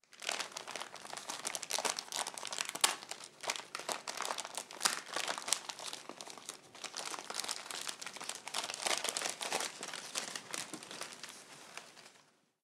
Abrir un regalo
papel de regalo
Sonidos: Acciones humanas